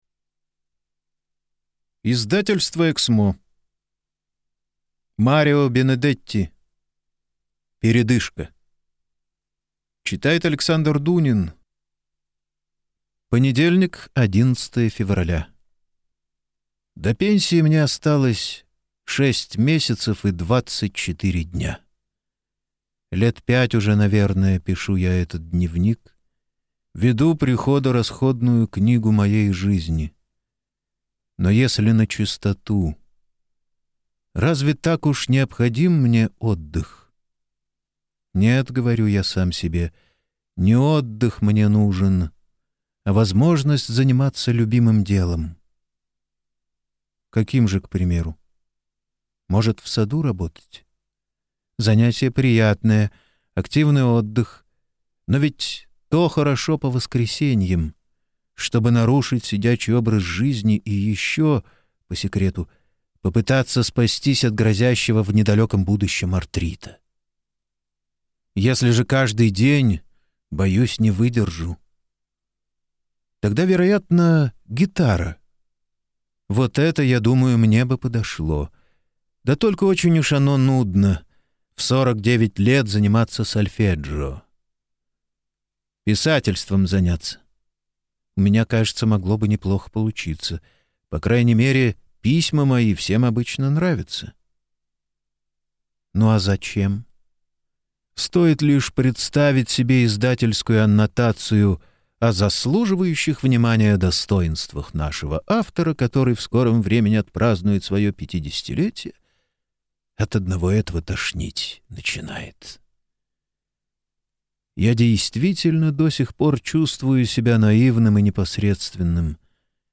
Аудиокнига Передышка | Библиотека аудиокниг